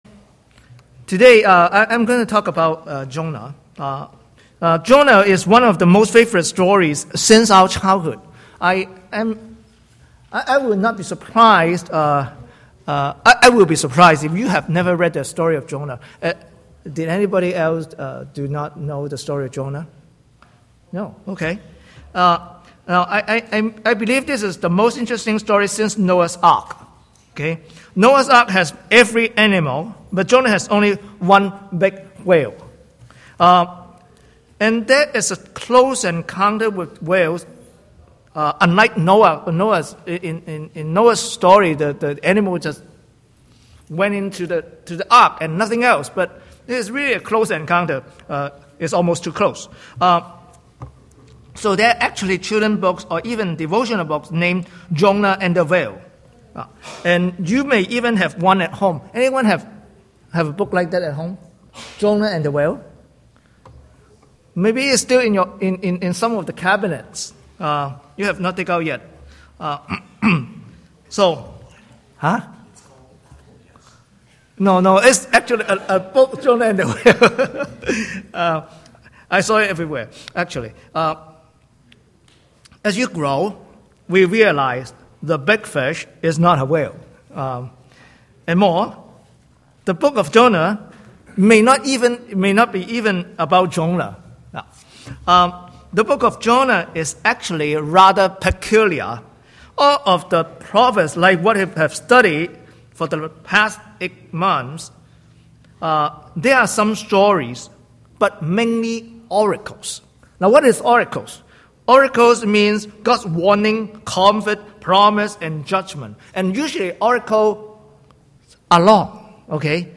Sermons | Lord's Grace Church